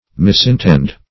Misintend \Mis`in*tend"\, v. t.